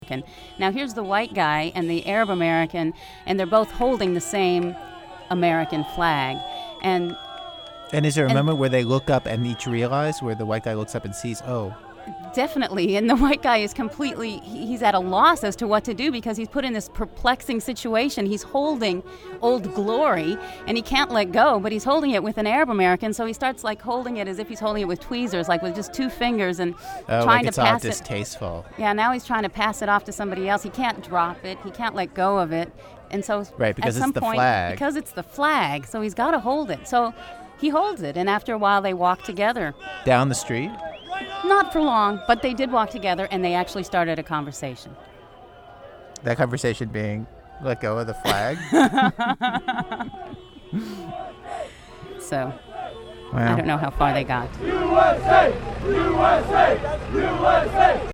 It seemed, for a moment, he was actually reporting on some affect of the aftermath of the attacks, with a reporter on the street interacting with the public and he in the studio asking questions of her.
When I heard him say it, I could also hear his eyes roll.